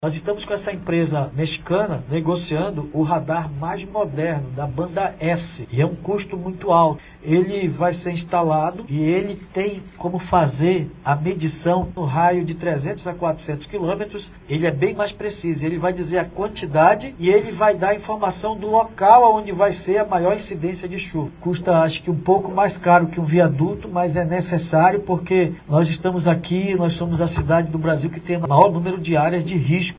SONORA-2-PREFEITO.mp3